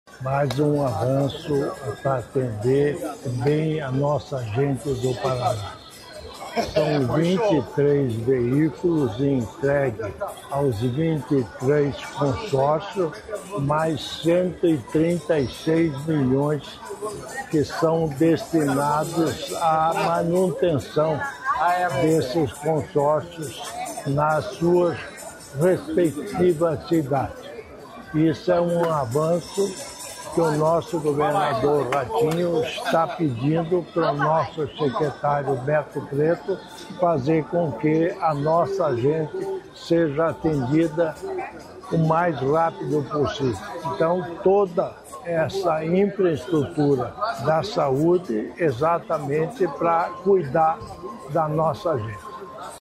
Sonora do vice-governador, Darci Piana, sobre aumentar atendimentos dos consórcios | Governo do Estado do Paraná